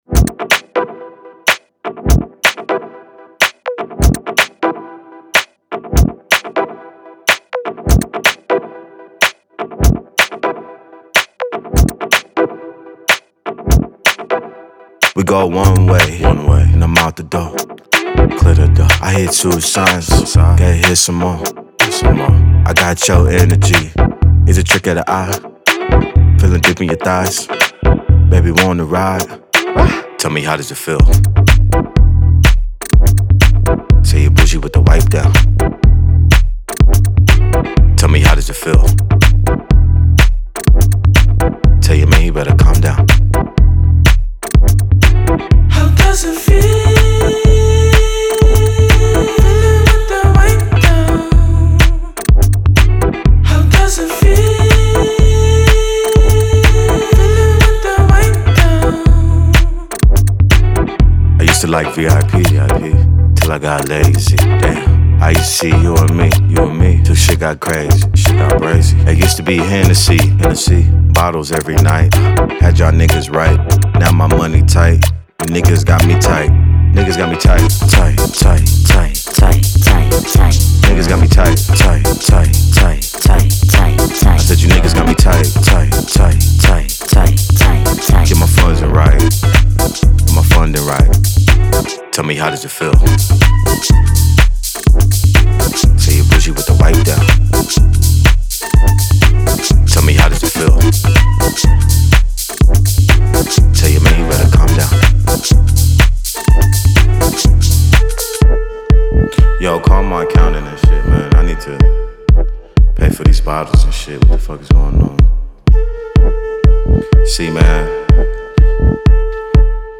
• Категория:Басы